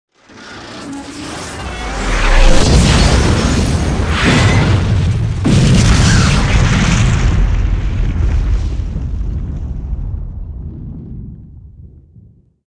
csx_monster01.wav